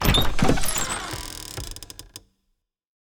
open-creaky.ogg